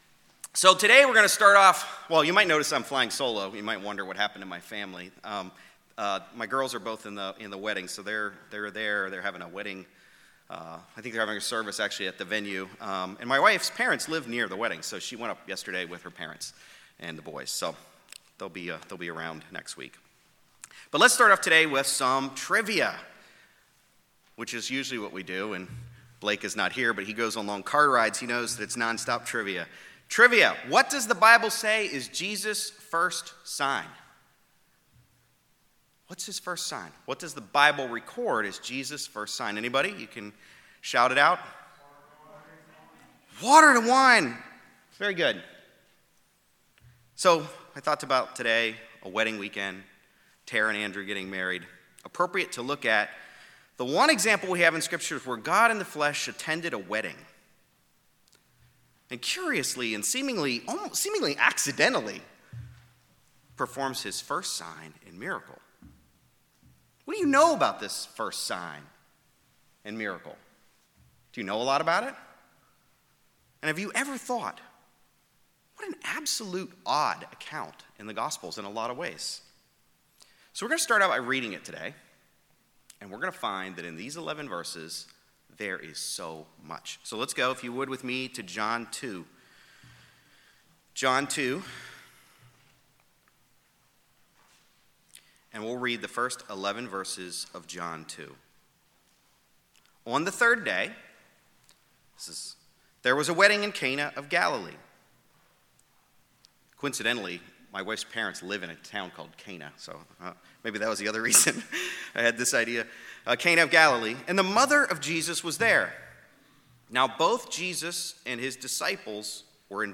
Given in Raleigh, NC